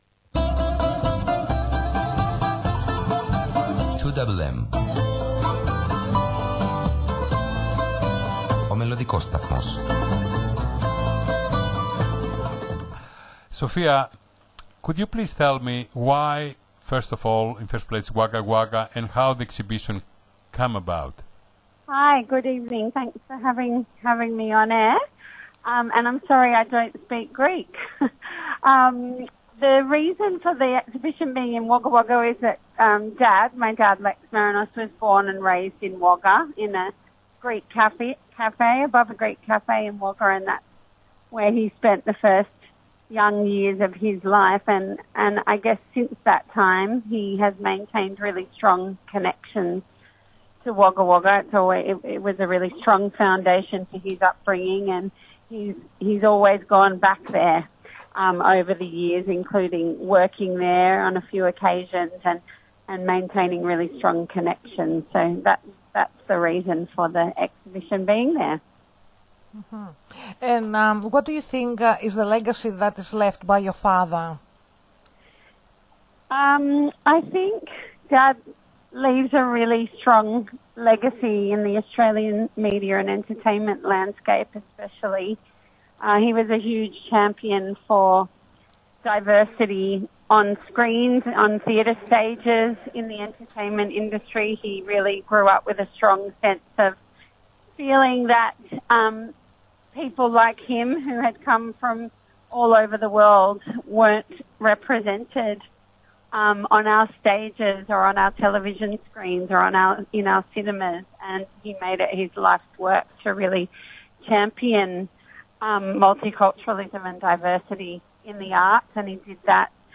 στο ελληνόφωνο ραδιόφωνο https